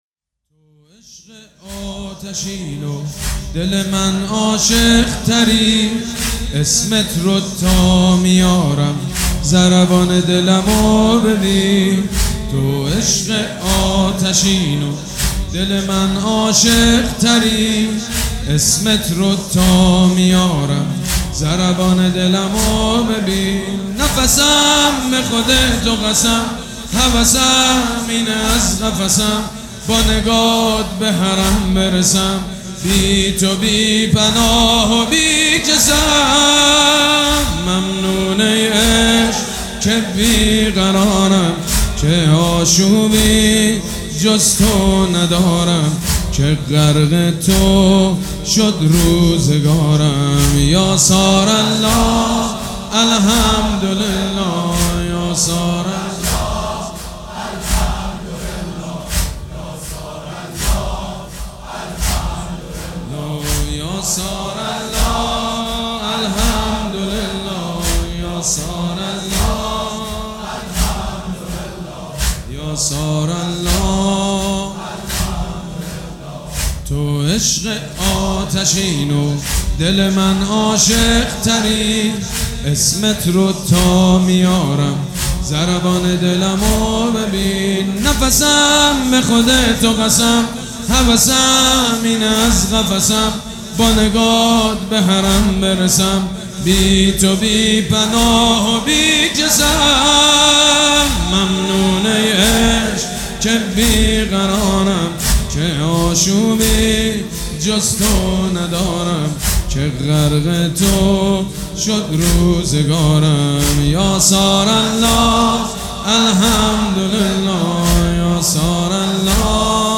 مداح
مراسم عزاداری شب عاشورا